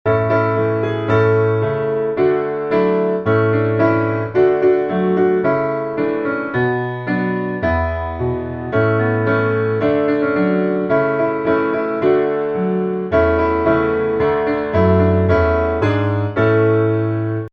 A Major